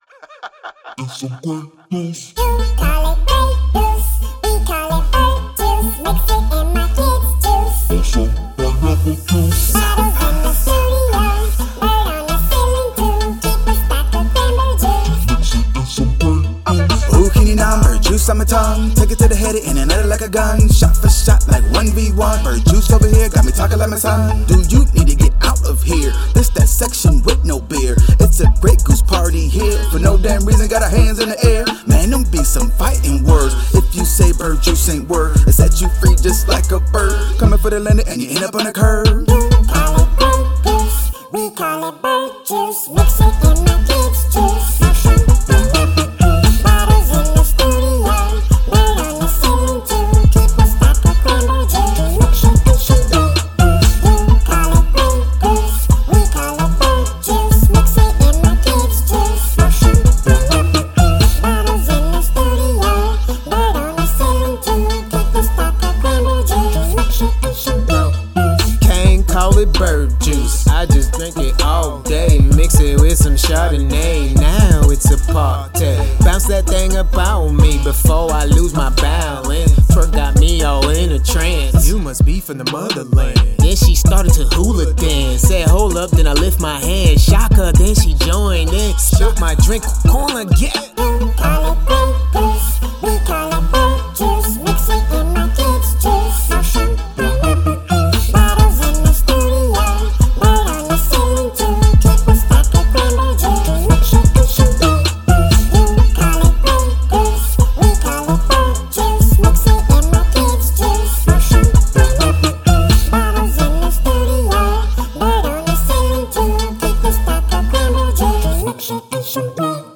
Hip-hop, Pop, Rhythm and Blues and Rap/Rock